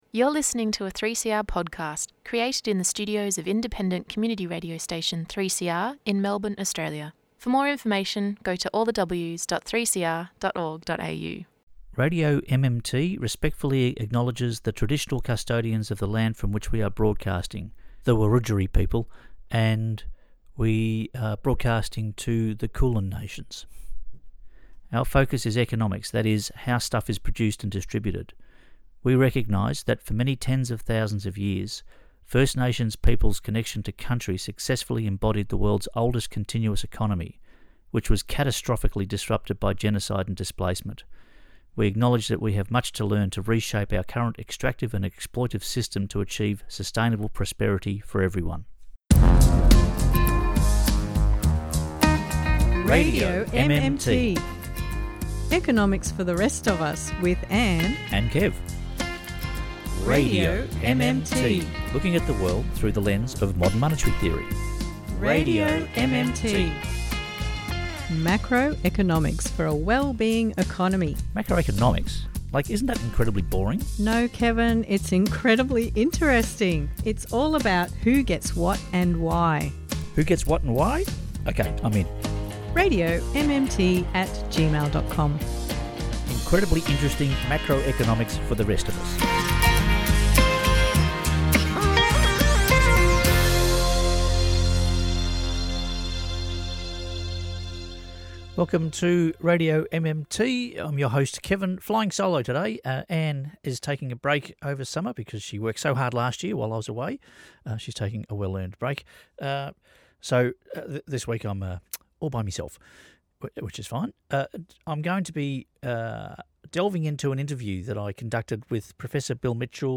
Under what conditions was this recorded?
This program on – RadioMMT – which is a weekly program on Melbourne’s community radio station 3CR was broadcast on January 23, 2026.